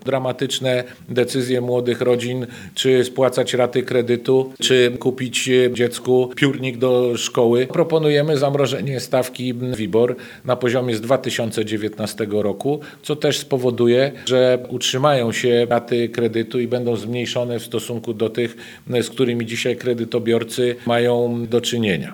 Politycy Nowej Lewicy podczas dzisiejszej konferencji prasowej przedstawili pakiet rozwiązań chroniących obywateli przed inflacją. Chodzi o dodatkową waloryzację rent i emerytur, podwyżki dla sfery budżetowej i kwestie związane z kredytami. O tych ostatnich mówi Dariusz Wieczorek.